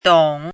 怎么读
dǒng
dong3.mp3